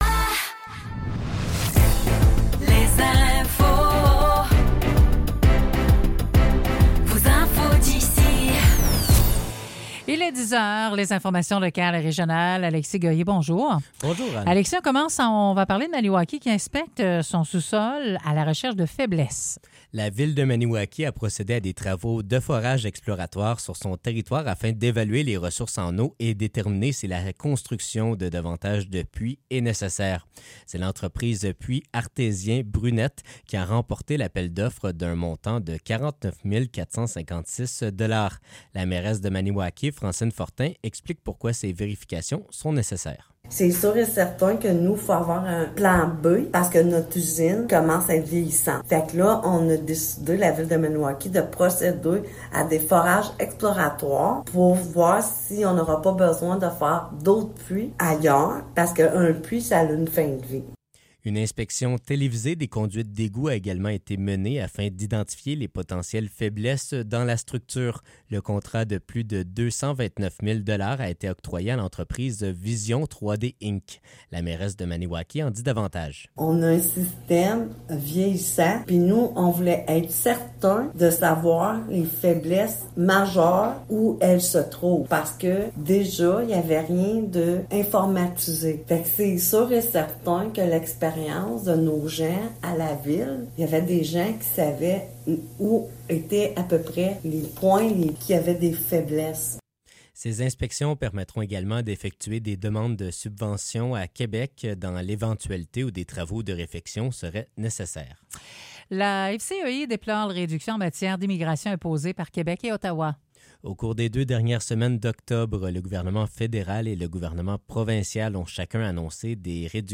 Nouvelles locales - 5 novembre 2024 - 10 h